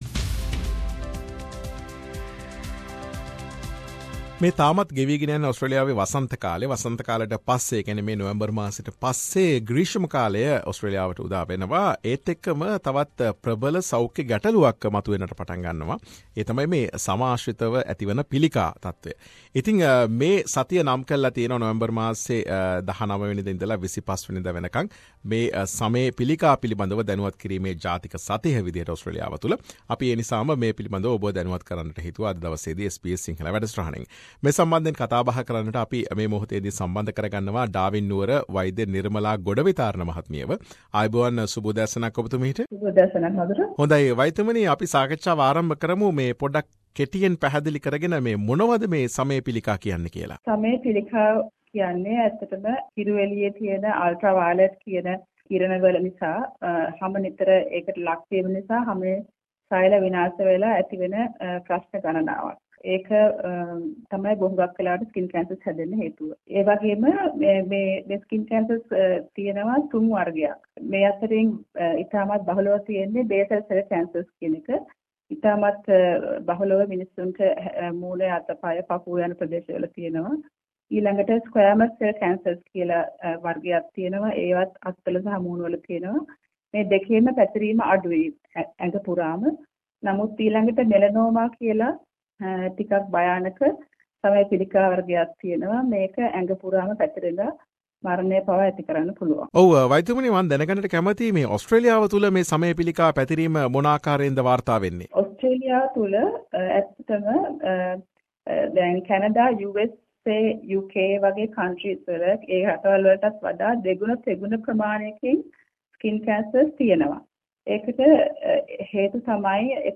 Health discussion